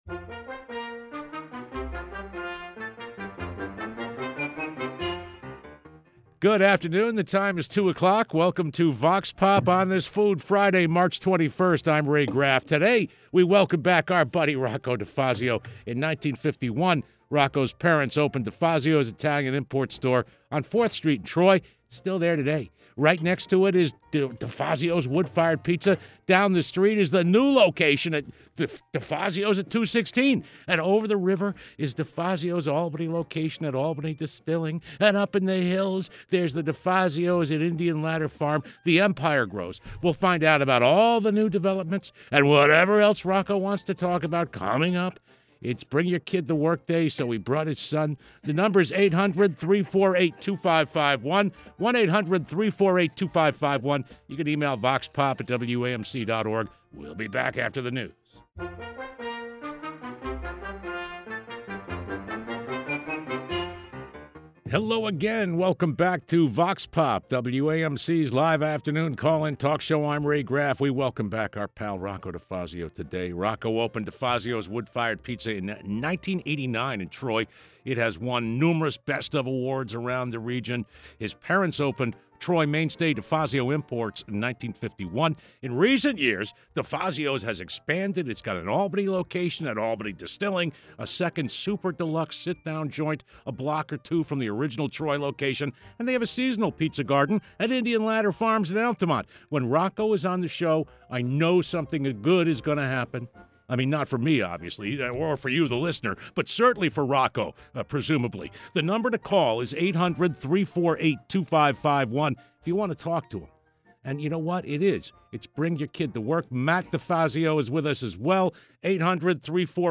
Vox Pop is WAMC's live call-in talk program.
Our experts take questions posed by WAMC listeners.